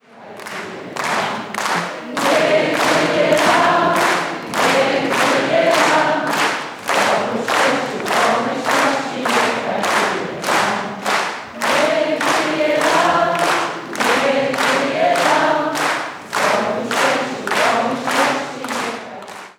Benefis Andrzeja Strumiłły odbył się w sobotę (21.10) w Suwalskim Ośrodku Kultury.